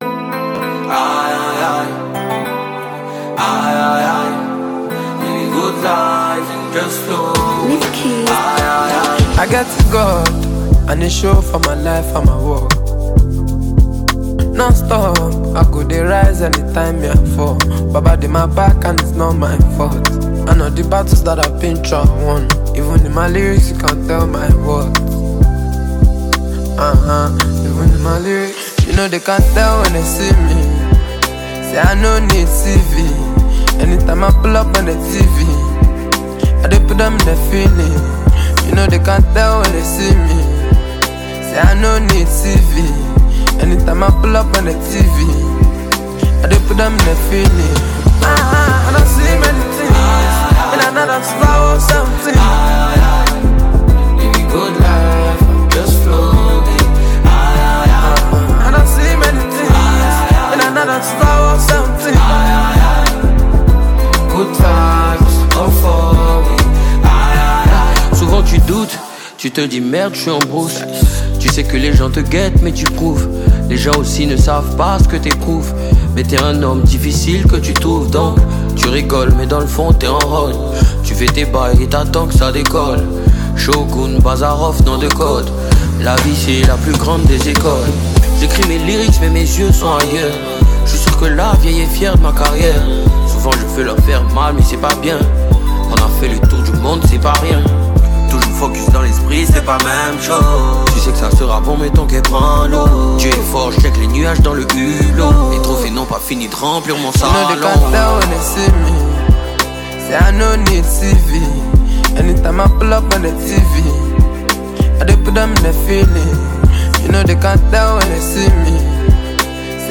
Talented Ivorian rapper